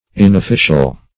Inofficial \In"of*fi"cial\, a.